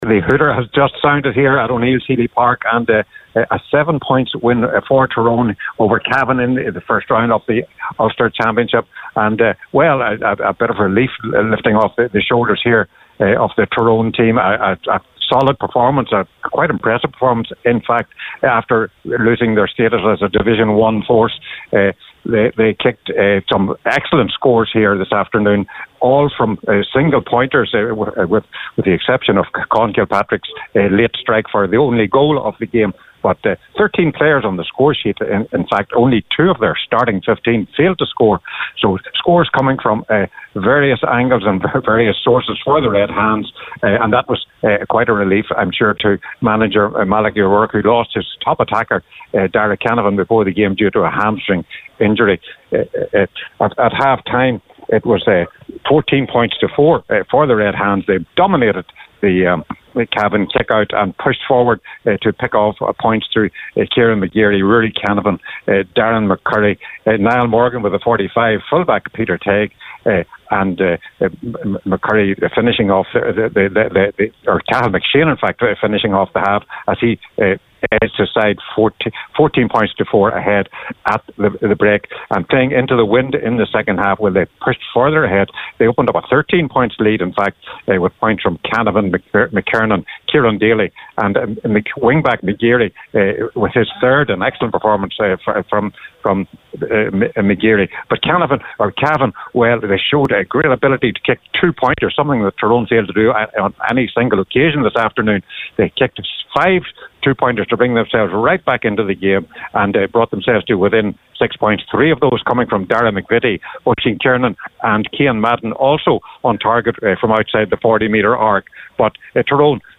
reported live from Omagh at full time for Highland Radio Sunday Sport…